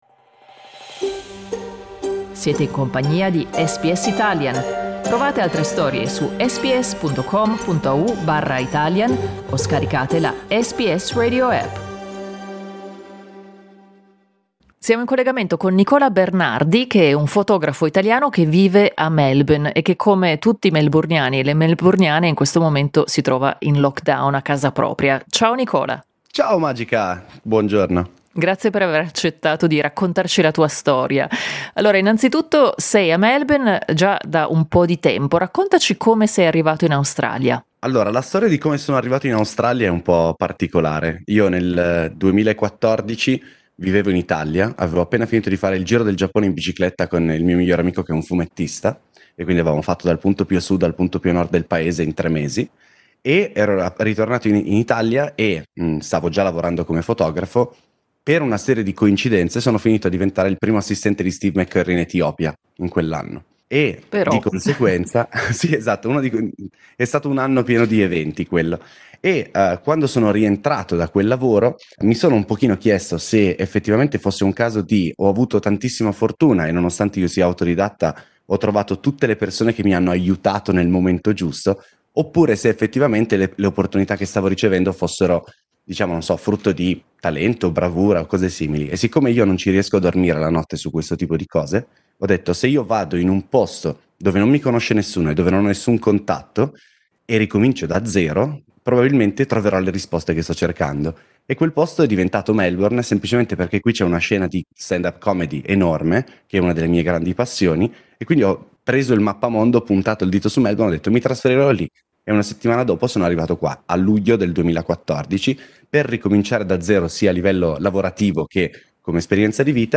Listen to his interview in Italian: